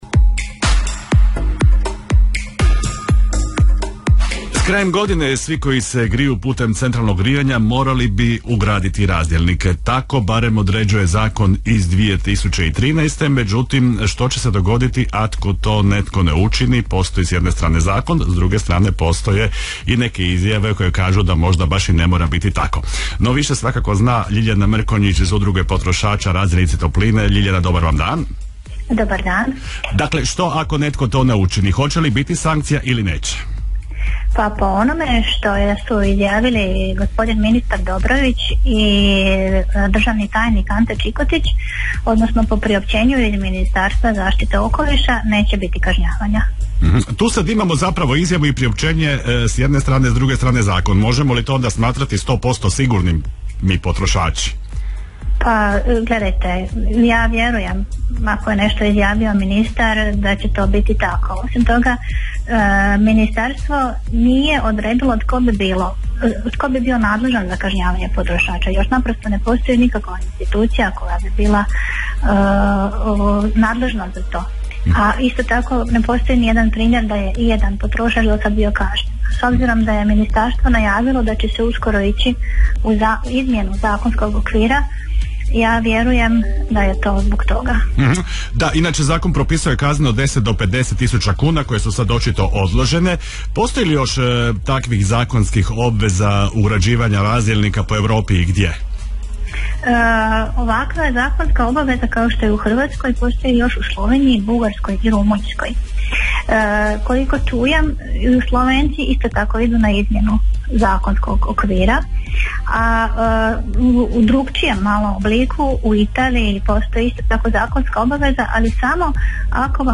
radio-rijeka_audio.mp3